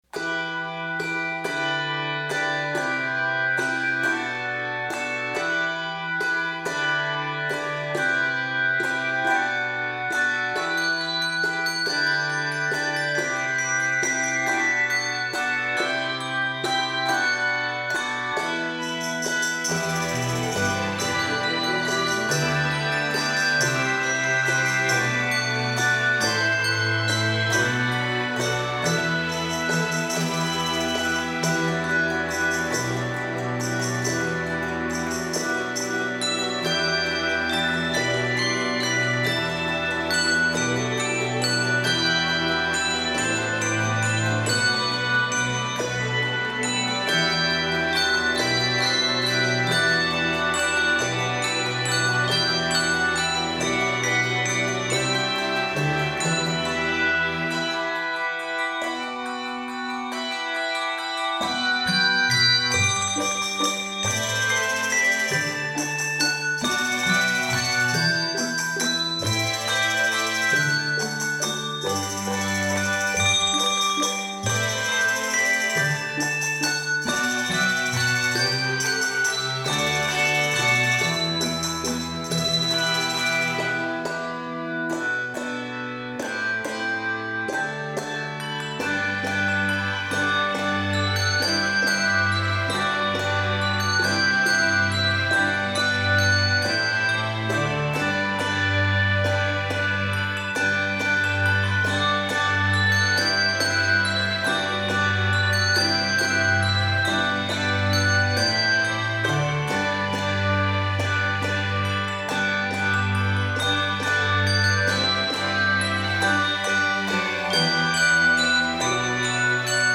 Key of e minor and G Major.